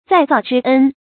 再造之恩 zài zào zhī ēn 成语解释 再造：再生。